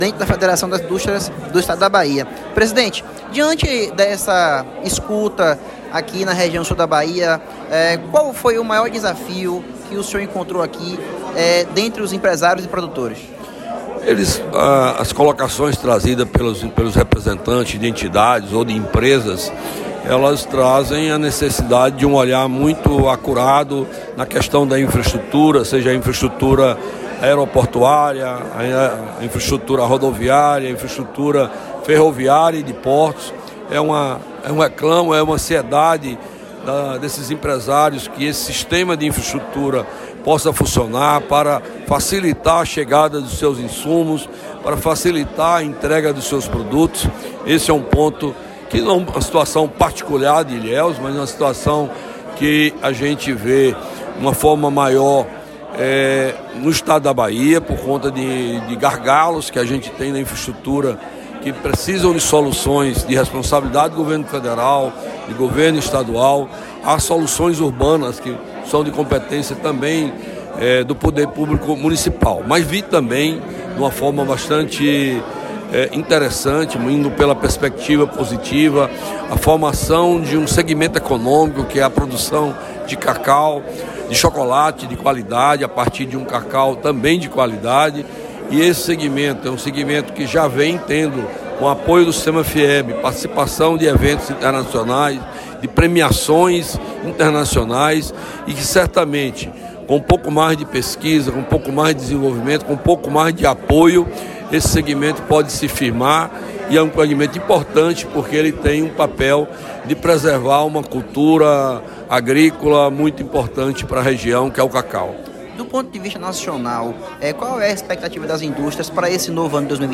Em uma entrevista exclusiva